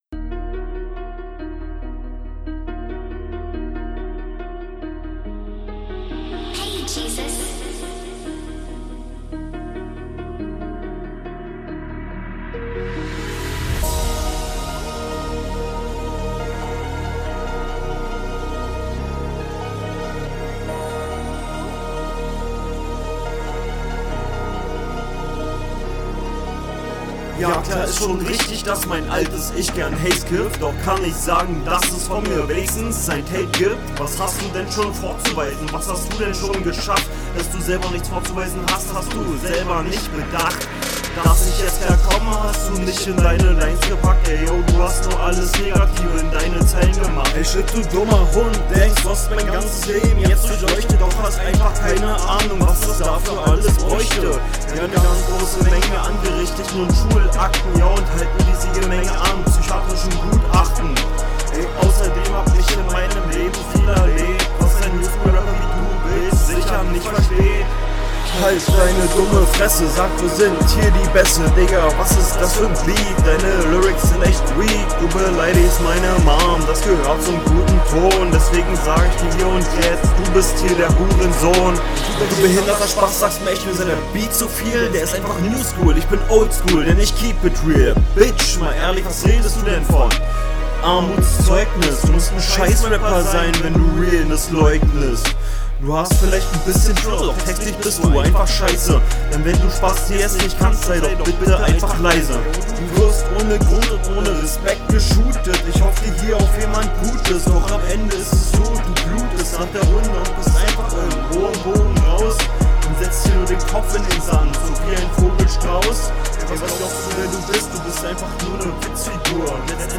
Puh, Flow hakelt, man versteht in den Parts kaum was, ist der Beat zu laut? …
Krank offbeat - sehr schwer sich das ganze anzuhören...
Soundqualität und Flow sind unter aller Sau, das ist echt nicht silberwürdig.